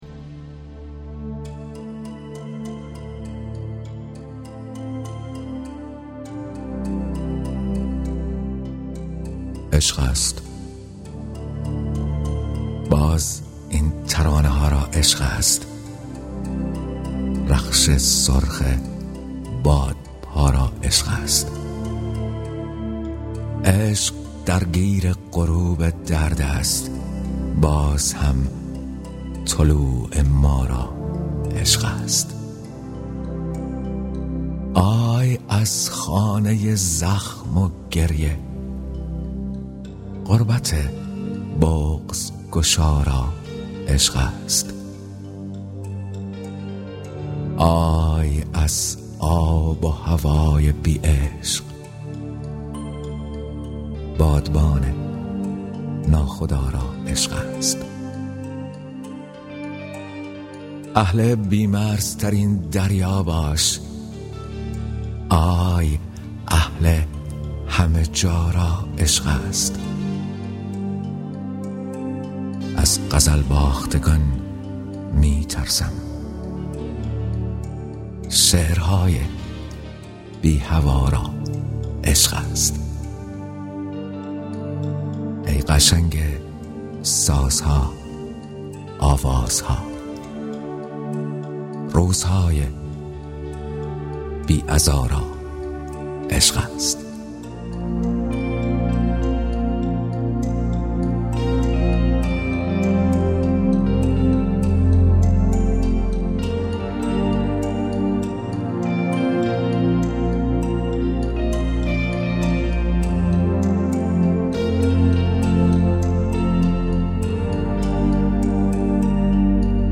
دانلود دکلمه عشق است با صدای شهیار قنبری
گوینده :   [شهیار قنبری]